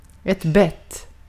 Ääntäminen
Ääntäminen : IPA : /ˈbɛt/ US : IPA : [bɛt]